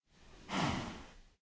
minecraft / sounds / mob / cow / say2.ogg